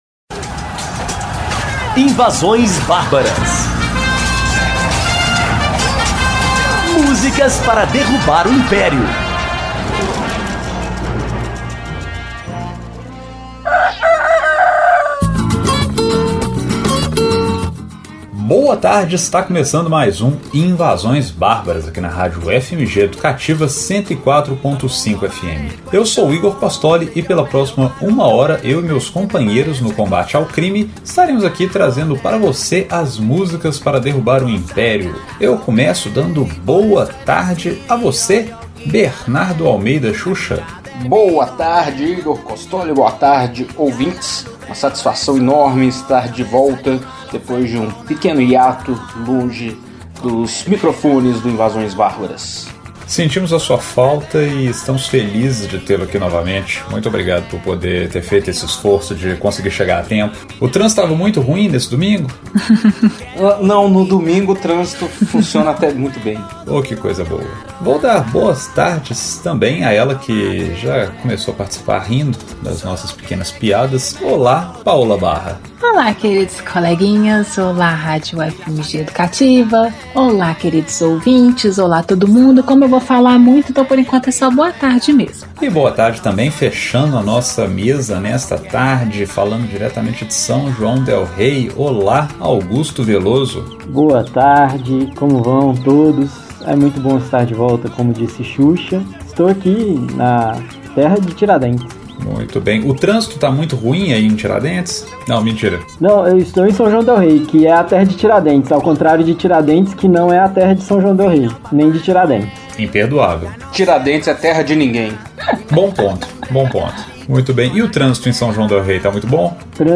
Invasões Bárbaras sofrendo com abstinência de festa junina resolveu colocar o pezinho na roça, desbravar terras de ninguém e enfrentar o típico trânsito de cocotas em pleno domingo para trazer até você, querido ouvinte, uma programação bucólica, caipira, country, folk do sertão com aquela pitadinha de rock (e punk, por interferência do coleguinha). Hoje é dia de Rock Rural!